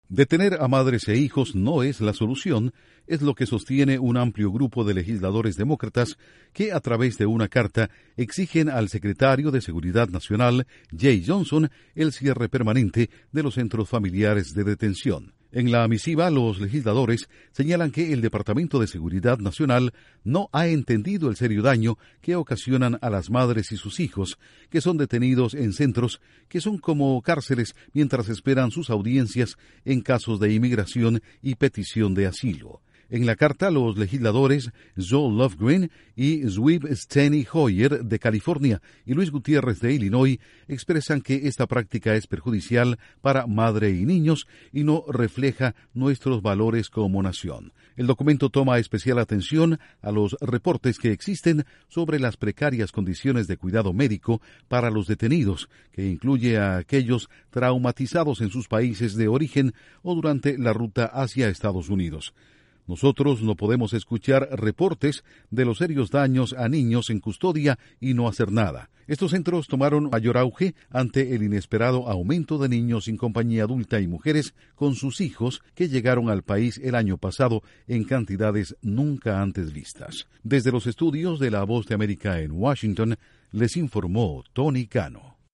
Legisladores de la Cámara de Representantes piden que se cierren los centros de detención familiares en Estados Unidos. Informa desde los estudios de la Voz de América en Washington